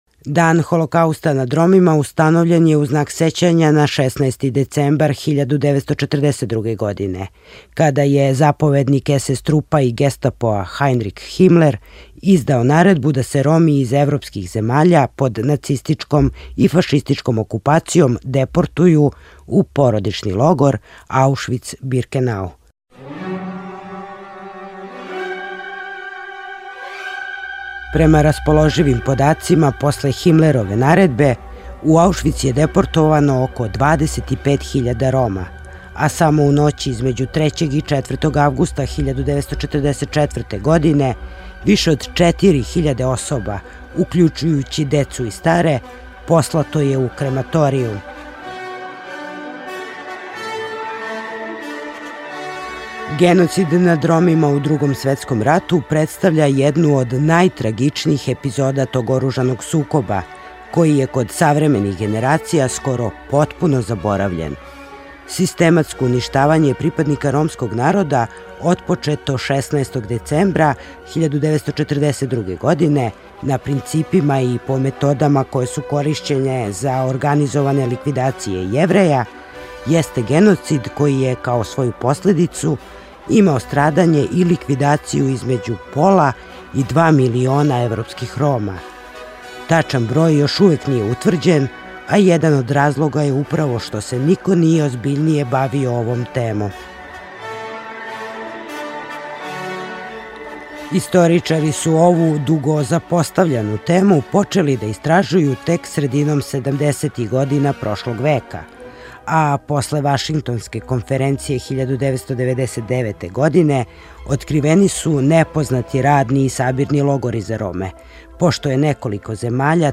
"Zaboravljeni Holokaust", reportaža o stradanju Roma